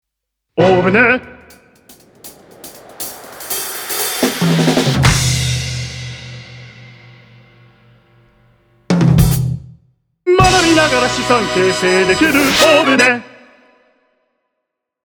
TVCM
JINGLE / SOUND LOGO
演奏のニュアンスにさりげなく効果音を重ね、画と音が心地よく溶け合う仕上がりを目指しました。
冒頭とラストのサウンドロゴでは、オペラ調の合唱を用いて、やわらかくも印象に残る余韻をつくりました。
Drums
Vocal